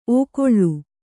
♪ ōkoḷḷu